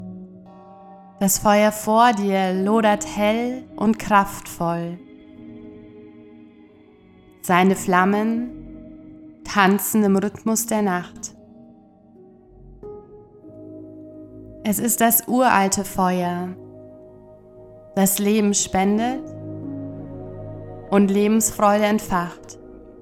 Beltane-Meditation: Entfache Dein Lebensfeuer, geführte Meditation, Themen: Feuer - Lebensfreude - Magie.
Begleitet von beruhigender Musik und einfühlsamen Anleitungen wirst Du in einen Zustand der tiefen Entspannung und spirituellen Verbundenheit eintauchen.